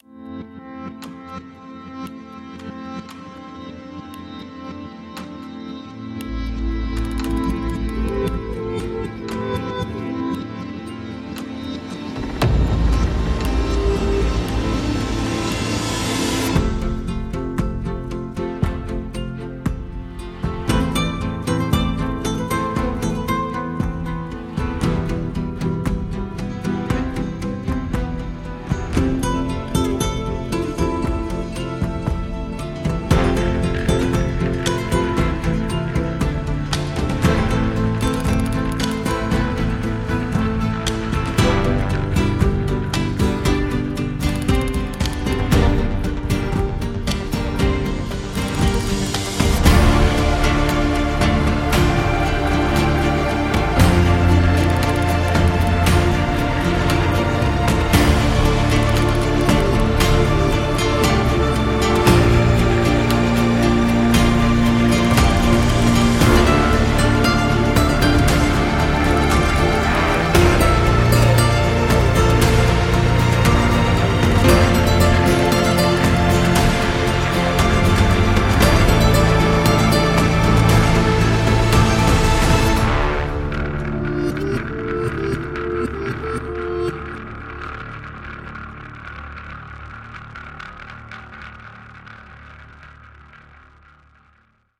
Nylon＆Steel
–情感原声吉他
弦乐短语固定您的乐谱–尼龙和钢弦踏板可增加提示
音的动感和张力–原声Guitarscapes将原始有机与混合声音设计融合在一起